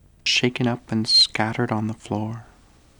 Processing: granulated, dur/del = 8, 50, 50 ms, then 8, 340, 300 @ 5:1